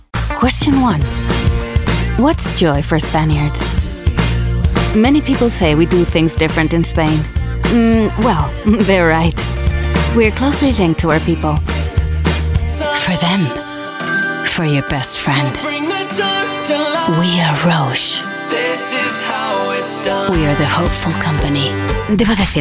Locutor masculino francés profesional que ofrece actuaciones en francés e inglés internacional junto con inglés y sueco con acento francés. Rango de edad de adultos jóvenes y voces extrañas / divertidas sin edad.